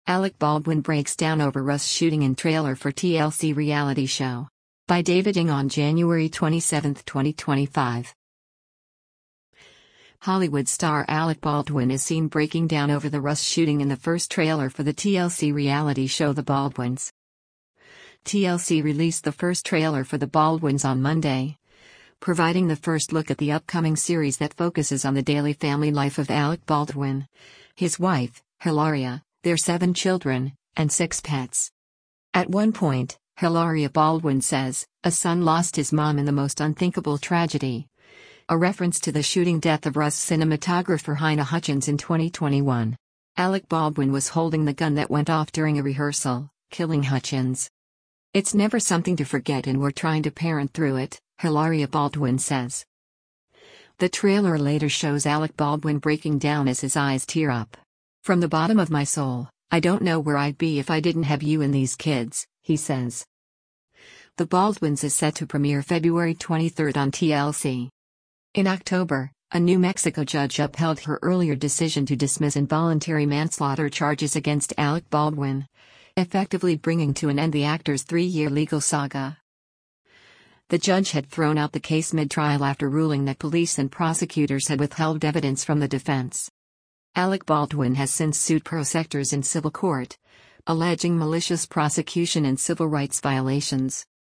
Hollywood star Alec Baldwin is seen breaking down over the Rust shooting in the first trailer for the TLC reality show The Baldwins.
The trailer later shows Alec Baldwin breaking down as his eyes tear up.